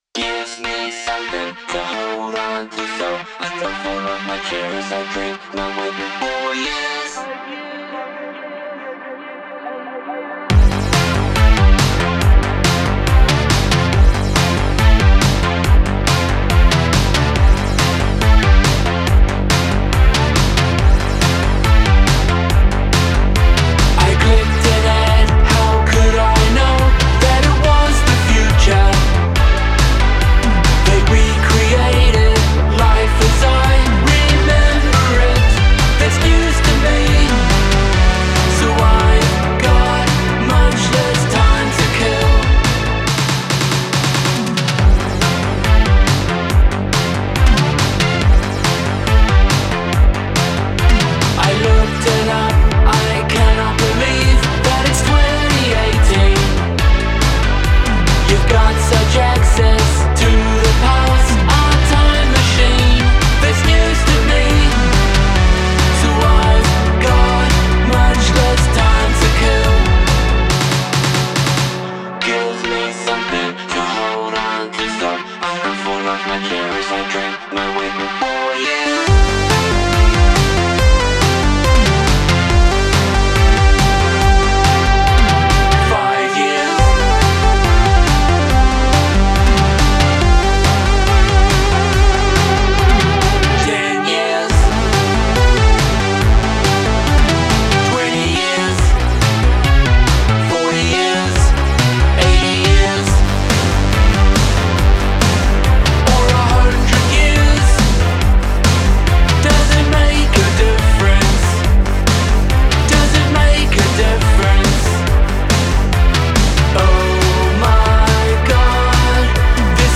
A synthpop banger 'News to Me'